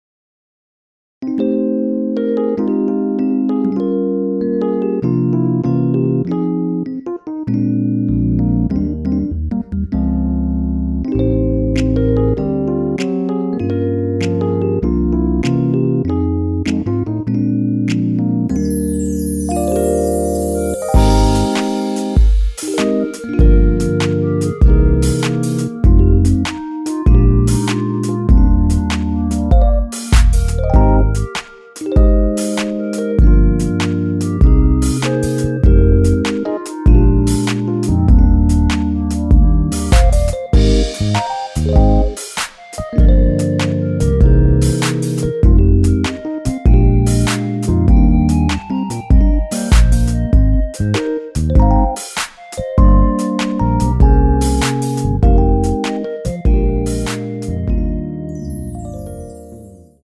원키에서(-1)내린 멜로디 포함된 MR입니다.
Eb
노래방에서 노래를 부르실때 노래 부분에 가이드 멜로디가 따라 나와서
앞부분30초, 뒷부분30초씩 편집해서 올려 드리고 있습니다.
중간에 음이 끈어지고 다시 나오는 이유는